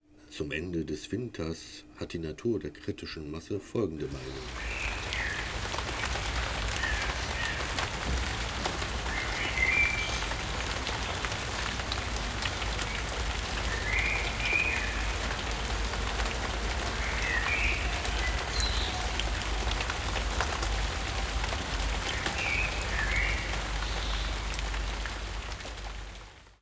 Der erste Vogel im Nachbargarten
Der Winter zwitschert Adé.